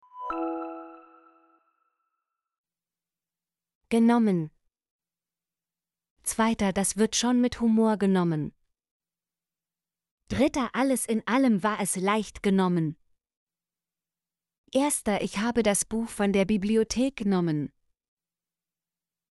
genommen - Example Sentences & Pronunciation, German Frequency List